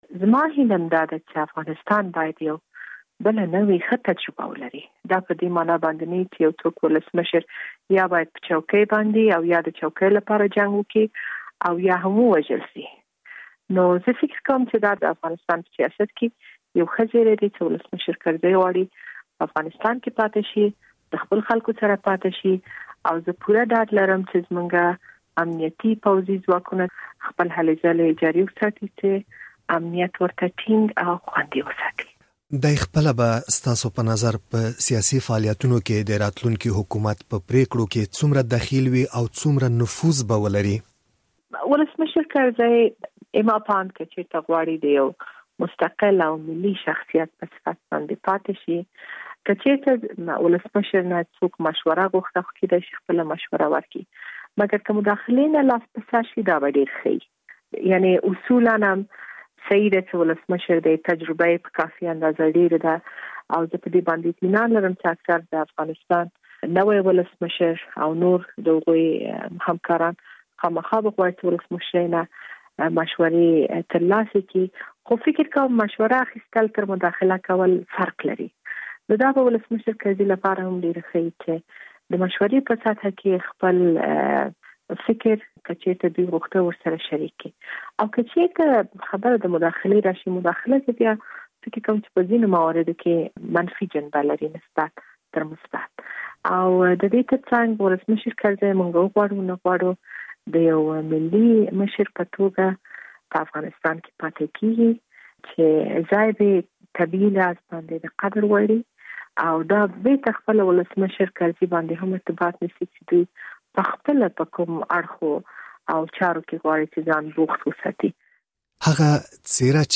له شکریې بارکزۍ سره مرکه